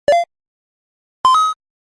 Free UI/UX sound effect: Level Up.
367_level_up.mp3